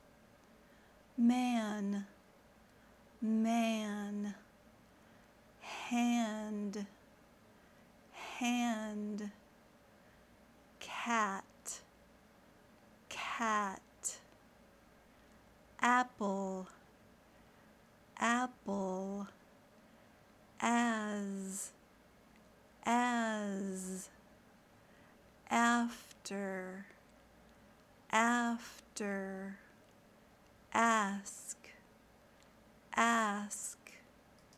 Practice Short “A”
short-a.mp3